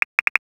NOTIFICATION_Pop_09_mono.wav